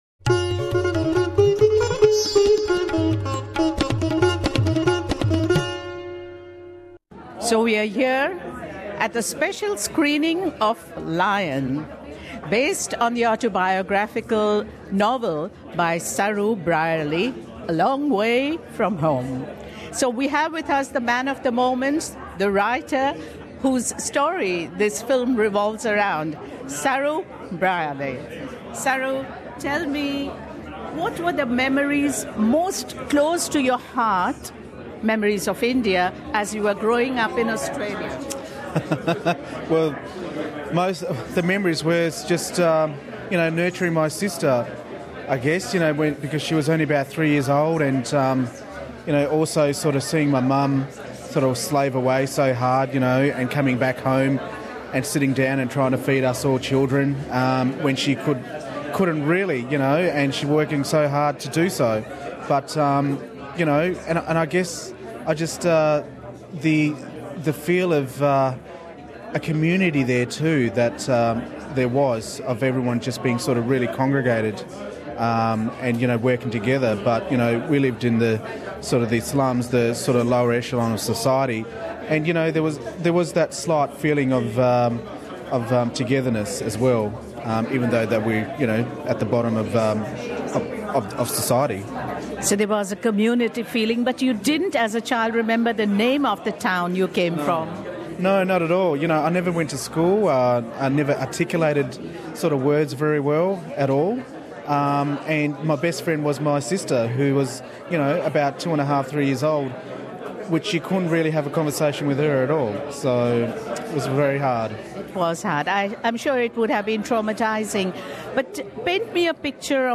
In an exclusive interview with SBS Hindi, Saroo talks about the ghosts that haunted him from his childhood in India. Saroo had mistakenly boarded a train at his village station at the age of 5 thinking that his older brother was on the train.